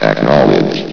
acknowledge.ogg